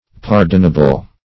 Pardonable \Par"don*a*ble\, a. [Cf. F. pardonnable.]